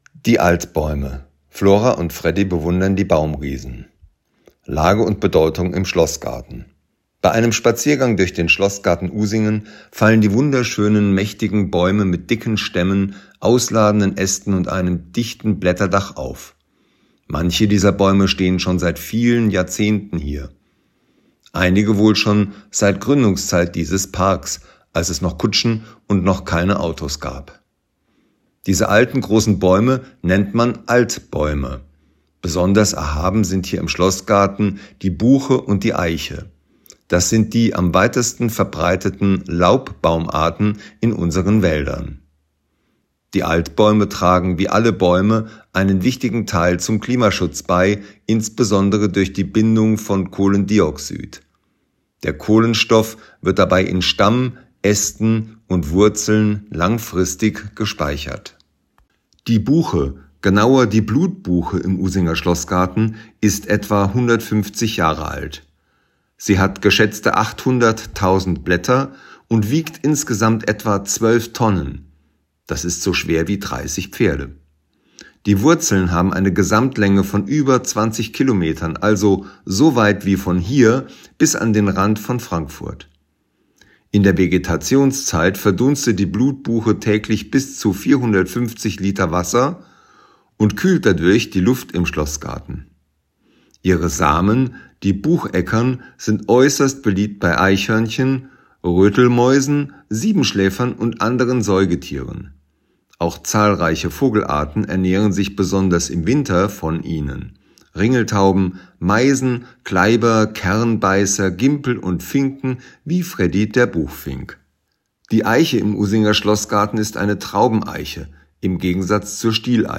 Wer nicht alle Schilder an den Stationen im Schlossgarten lesen möchte oder kann, kann sie sich hier einfach vorlesen lassen.